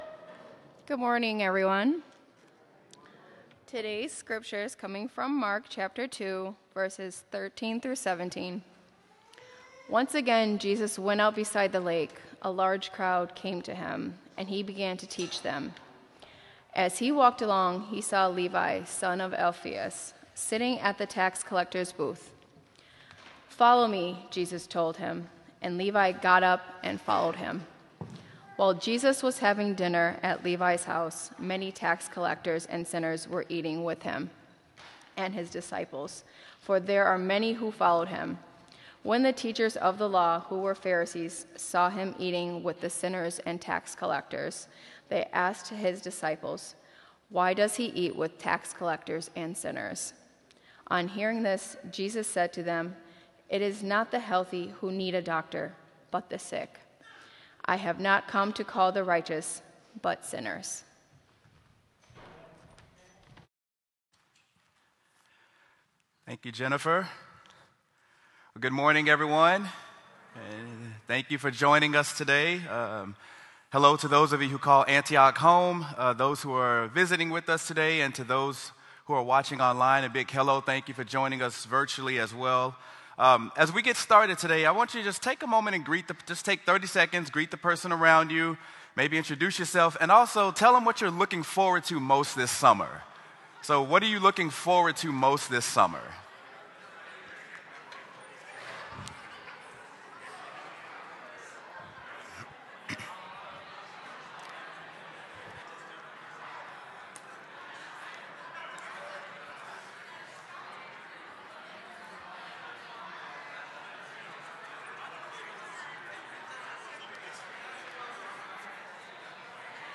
Sermon: Mark: Jesus Eats with Sinners
sermon-mark-jesus-eats-with-sinners.m4a